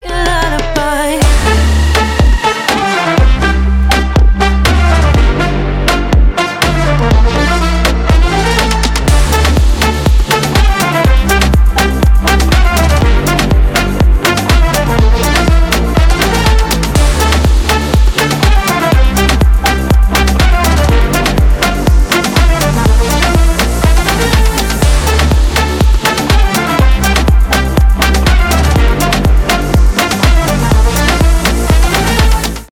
поп , балканские , румынские , танцевальные
заводные